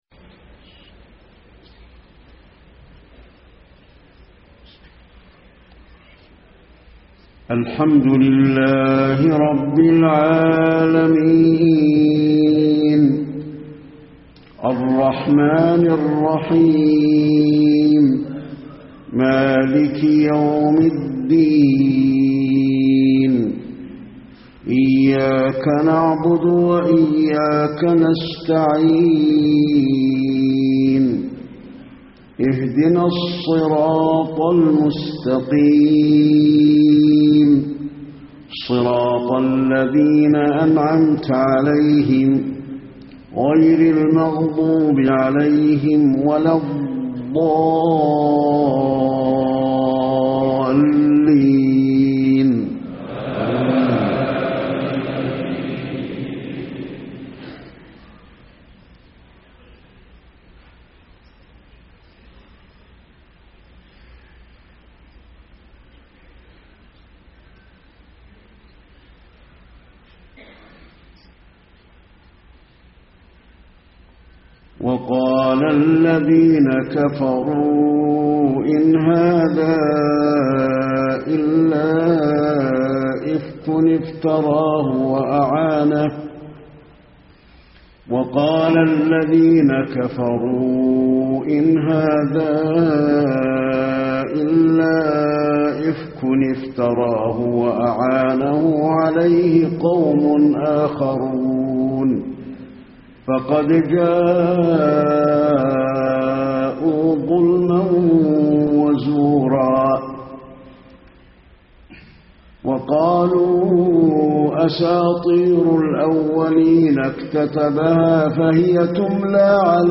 صلاة العشاء 8-6-1434 من سورة الفرقان 4-16 > 1434 🕌 > الفروض - تلاوات الحرمين